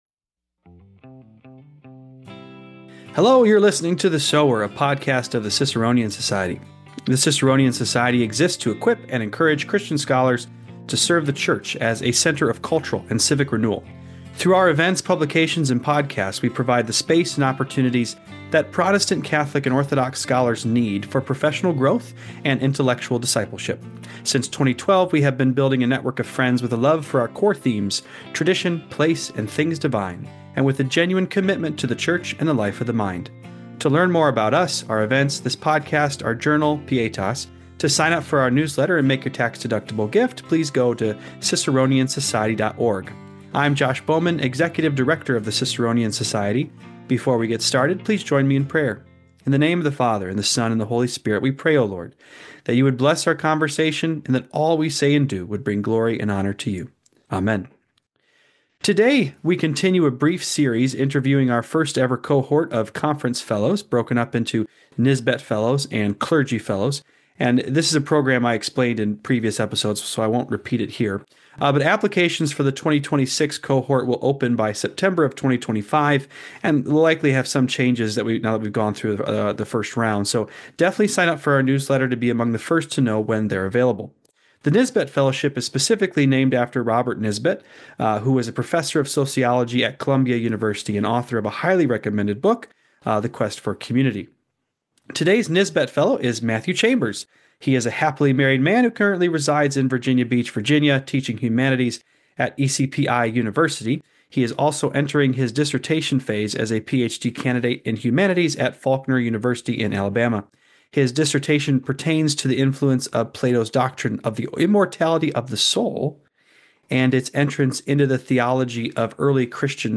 This episode concludes a series interviewing our first ever cohort of conference fellows.